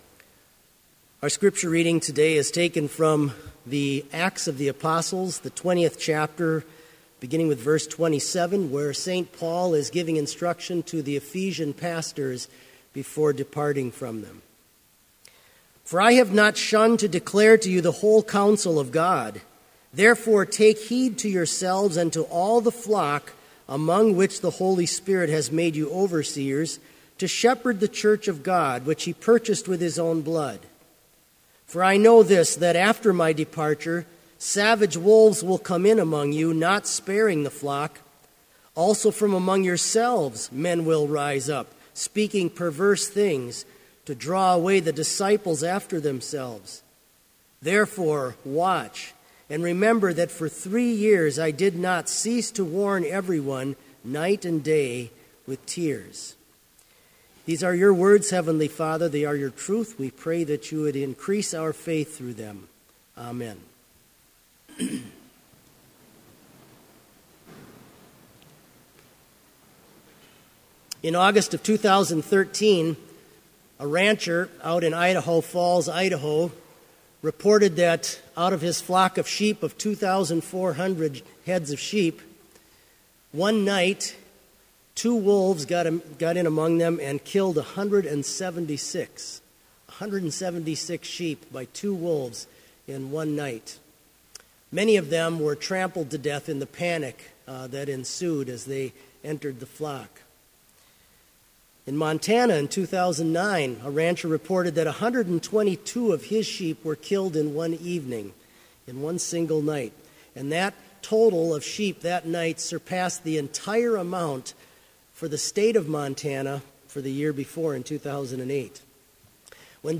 Chapel worship service held on April 15
BLC Trinity Chapel, Mankato, Minnesota
Complete service audio for Chapel - April 15, 2016